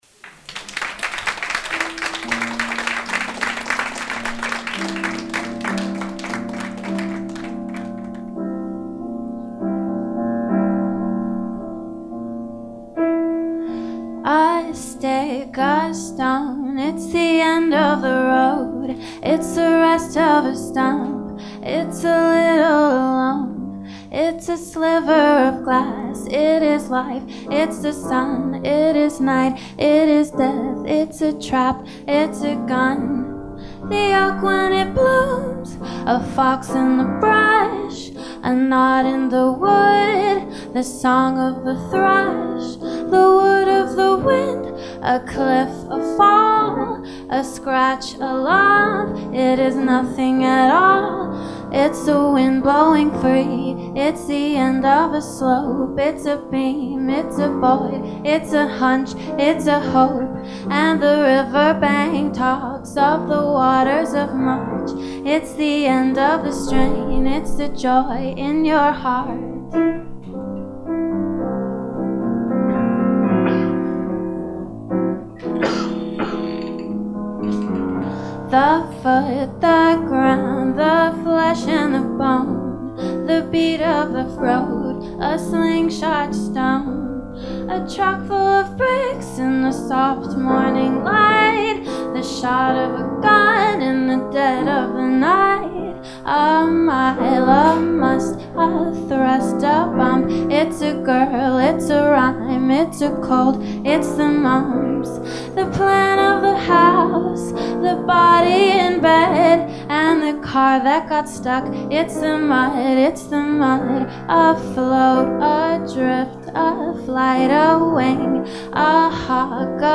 A Brazilian song composed by Antonio Carlos Jobim